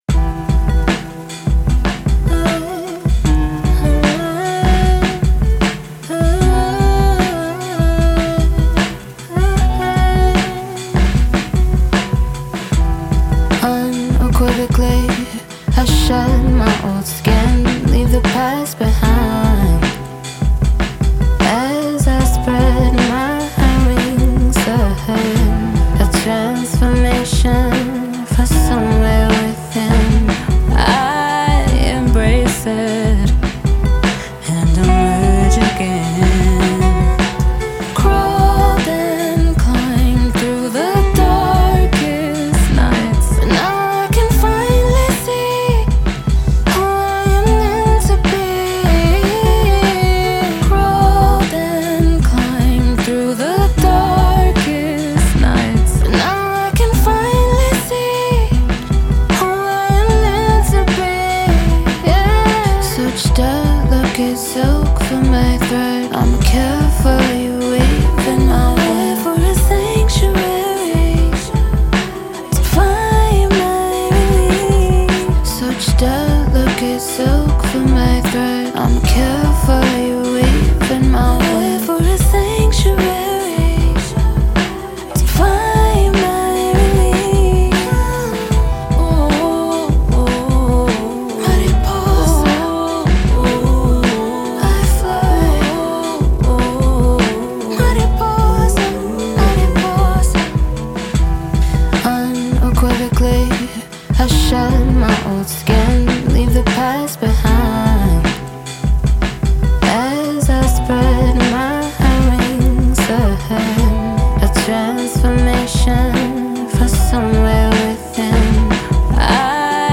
R&B, Alternative
E min